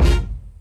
Game Synth Kick.wav